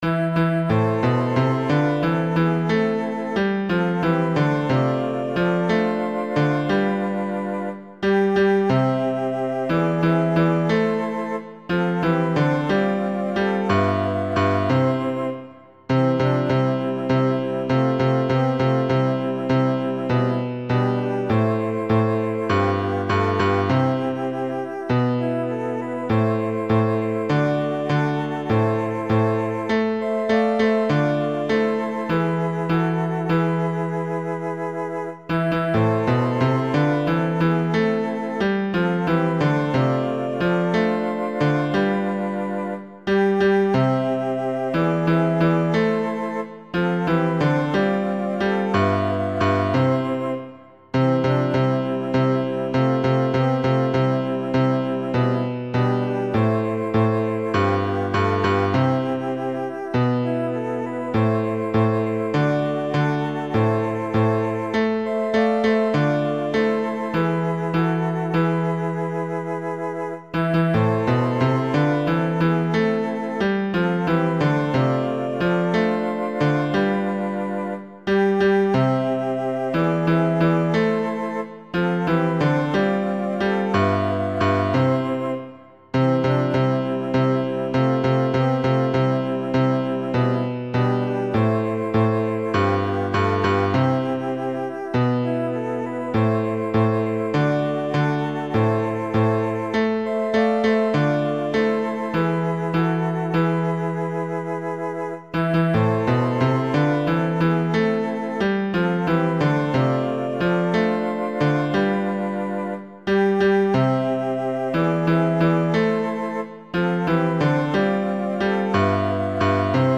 Vitesse : tutti Problème avec le tag audio 100 % soprane Problème avec le tag audio 100 % alto Problème avec le tag audio 100 % tenor Problème avec le tag audio 100 % basse Problème avec le tag audio 100 %
Si-tu-passes-basse.mp3